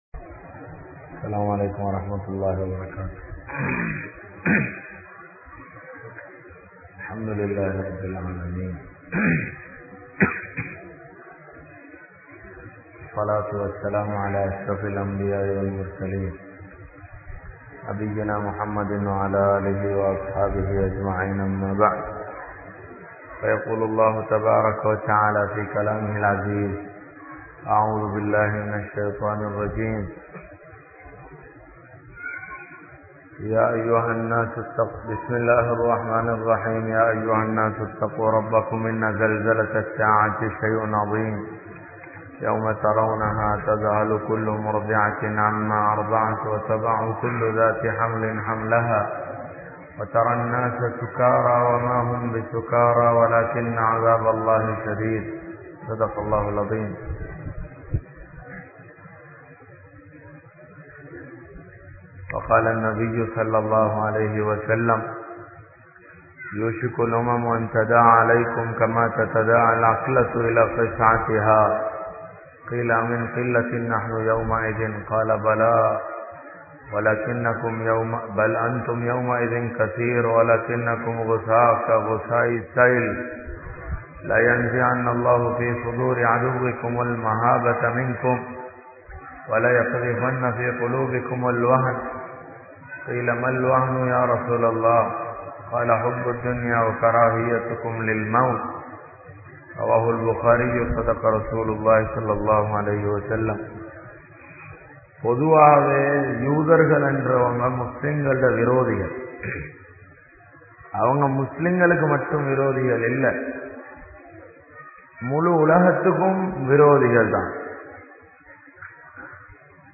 Samakalamum Islamum(சமகாலமும் இஸ்லாமும்) | Audio Bayans | All Ceylon Muslim Youth Community | Addalaichenai
Grand Jumua Masjidh